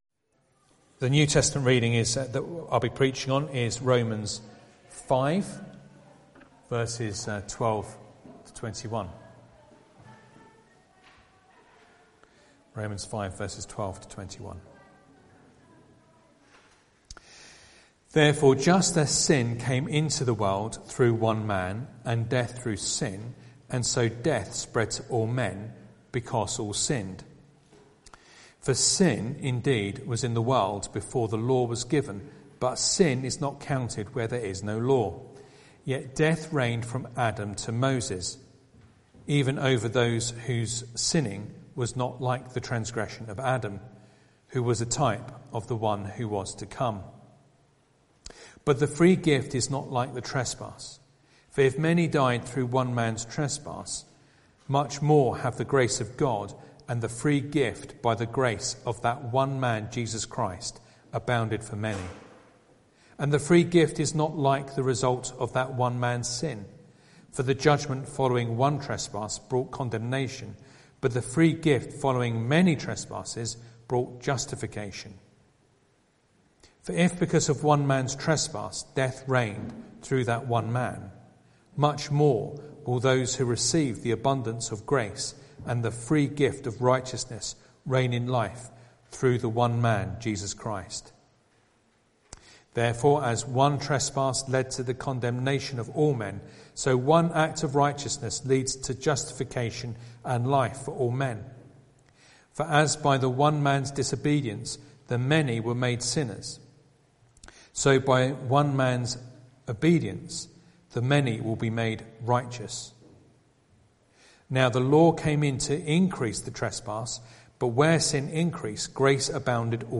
Romans 5:12-21 Service Type: Sunday Evening Reading and Sermon Audio